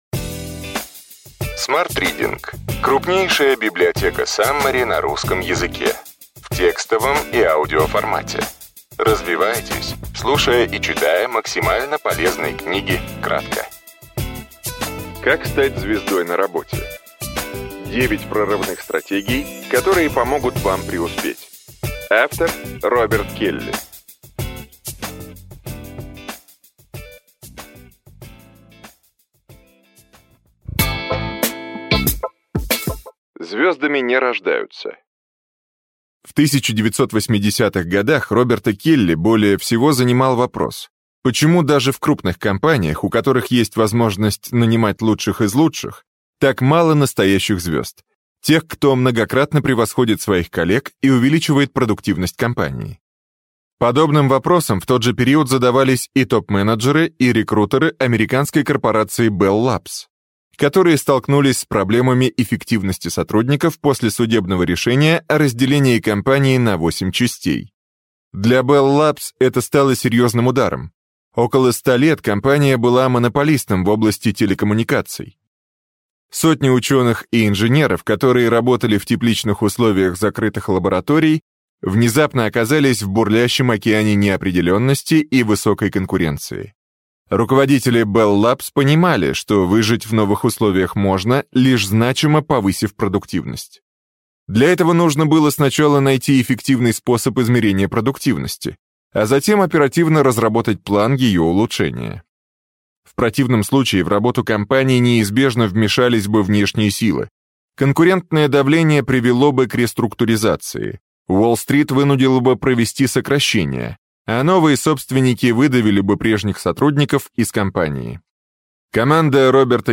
Аудиокнига Как стать звездой на работе. 9 прорывных стратегий, которые помогут вам преуспеть.